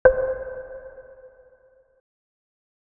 ButtonUp.wav